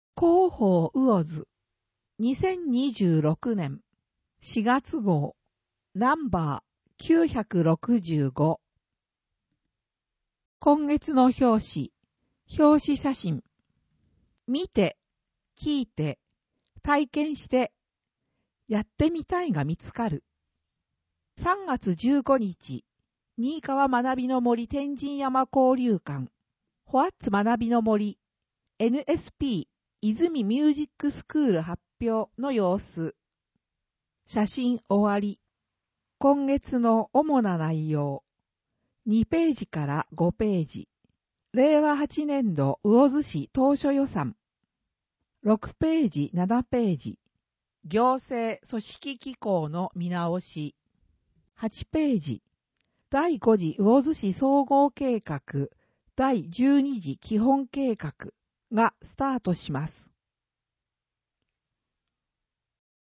声の広報
魚津市では、音訳サークルうぐいすの会にご協力いただき、視覚障害の方を対象に「広報うおづ」の音訳CDを無料で発送しています。